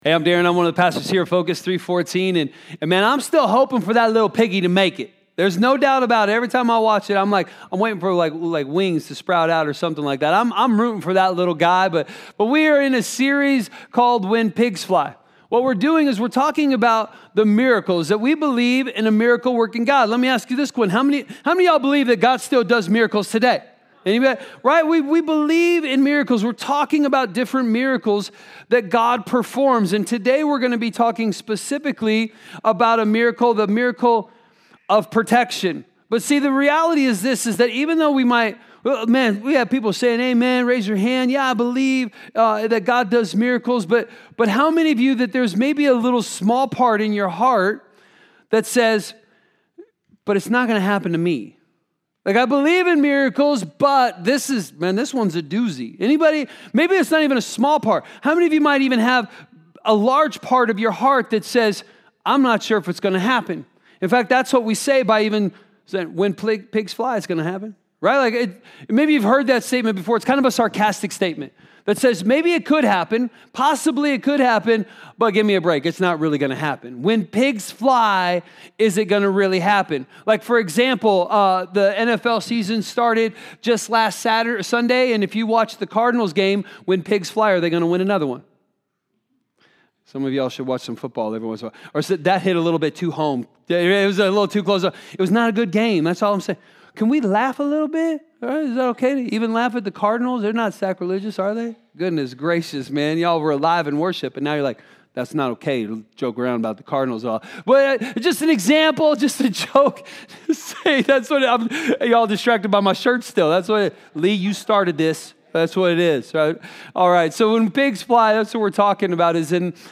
A message from the series "When Pigs Fly."